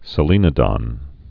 (sə-lēnə-dŏn, -lĕnə-)